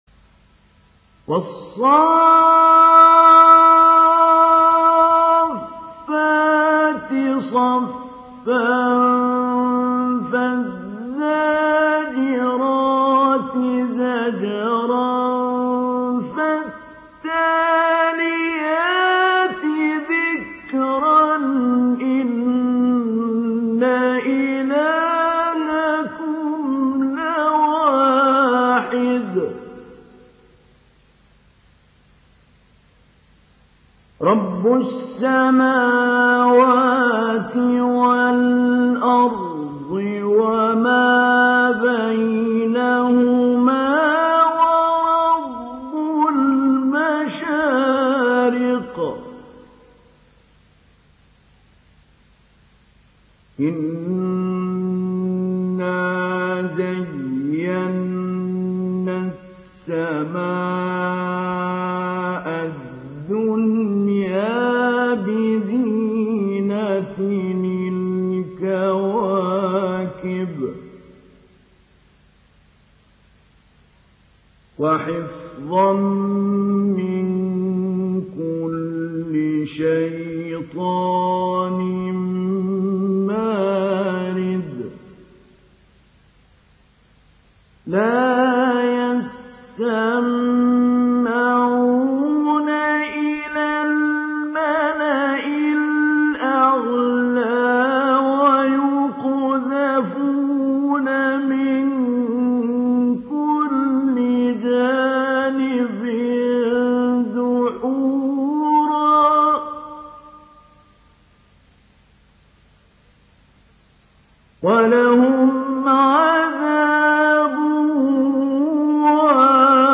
Download Surat As Saffat Mahmoud Ali Albanna Mujawwad